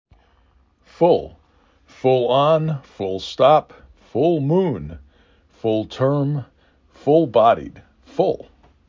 4 Letters, 1 Syllable
3 Phonemes
f u l